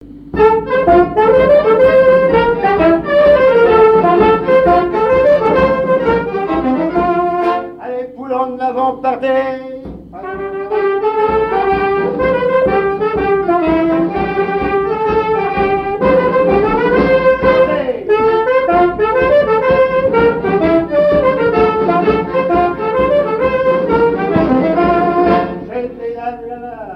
Mémoires et Patrimoines vivants - RaddO est une base de données d'archives iconographiques et sonores.
danse : quadrille : poule
Pièce musicale inédite